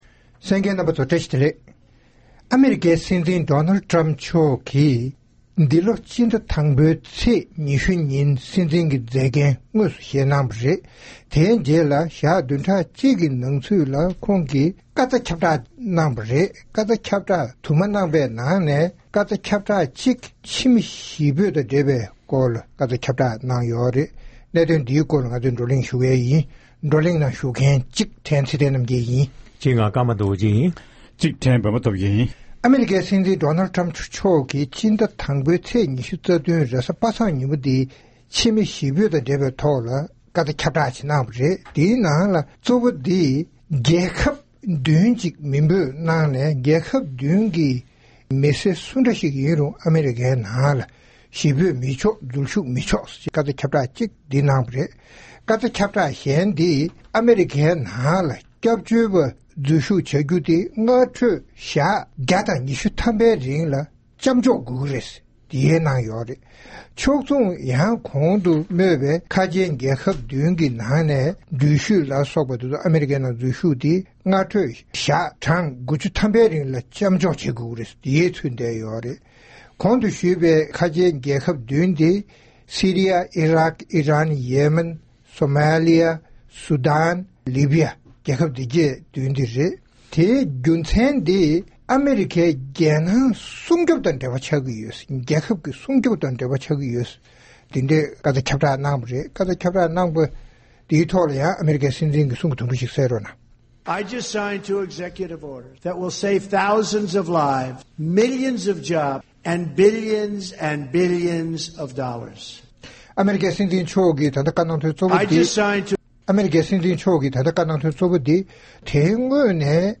༄༅། །ཐེངས་འདིའི་རྩོམ་སྒྲིག་པའི་གླེང་སྟེགས་ཞེས་པའི་ལེ་ཚན་ནང་། ཨ་རིའི་ནང་ཁྲིམས་འགལ་གྱི་ཕྱི་མི་གཞིས་ཆགས་བྱེད་མིའི་རིགས་ལ་དམ་དྲག་ཆེན་པོ་བྱེད་མུས་དང་། ཉེས་ཅན་འཛིན་བཟུང་གིས་ཕྱིར་འབུད་གཏོང་བཞིན་པ་སོགས་ཀྱི་སྐོར་རྩོམ་སྒྲིག་འགན་འཛིན་རྣམ་པས་གླེང་མོལ་གནང་བ་ཞིག་གསན་རོགས་གནང་།